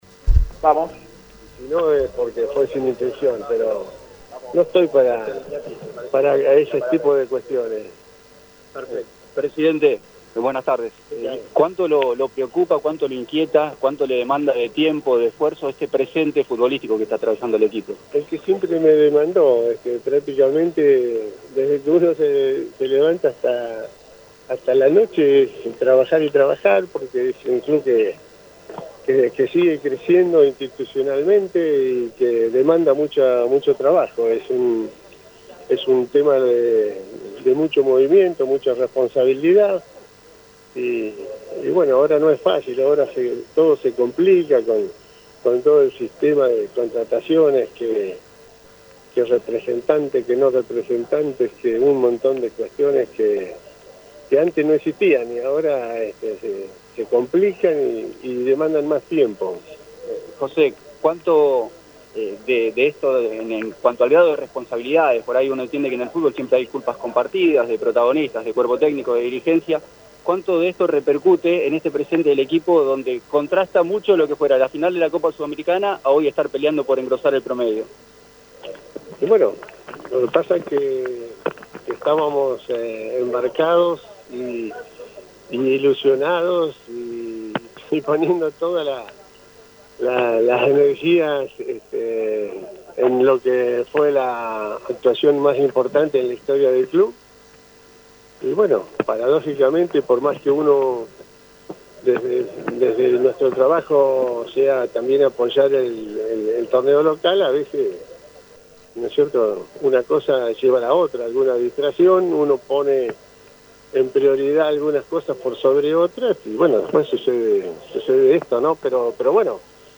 habló desde el estadio Norberto Tomaghello con la prensa